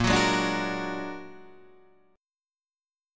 Bb+7 chord